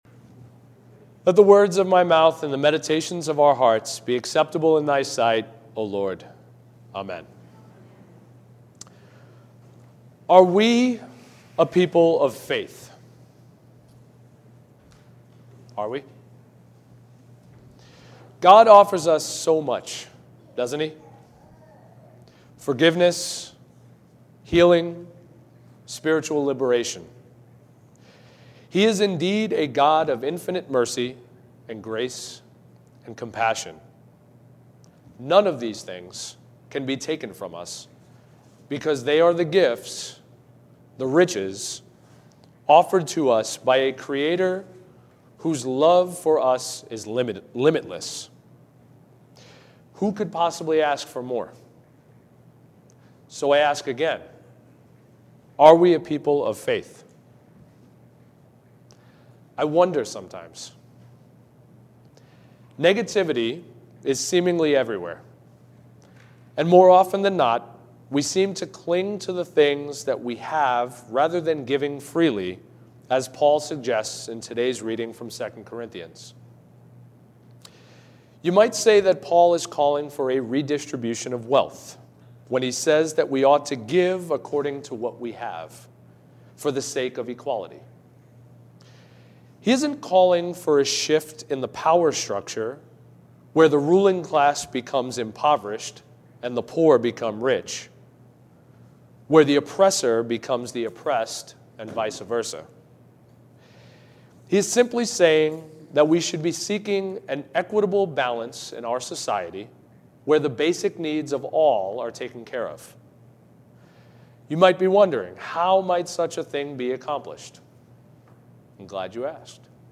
Weekly Services Passage: Mark 5:21-43, 2 Samuel 1:1, 2 Samuel 1:17-27 Service Type: Sunday Morning 9:30